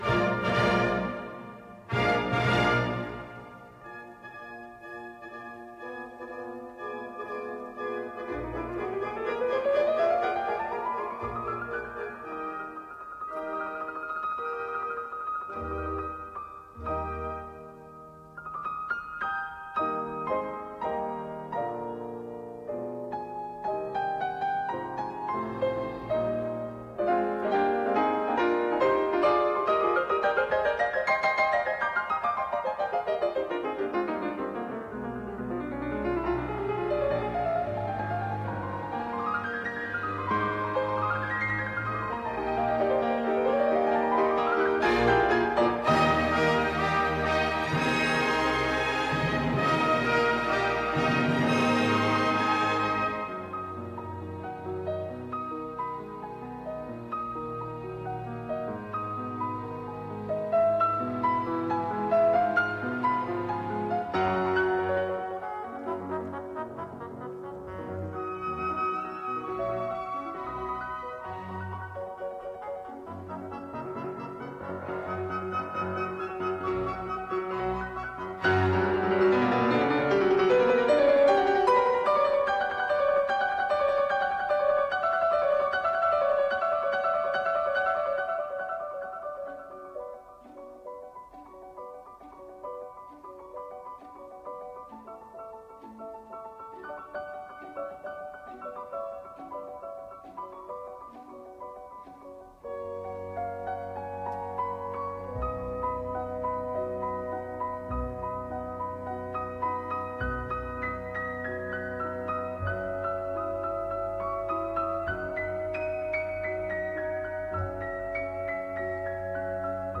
Piano
(Recorded April 1959, Manchester, England)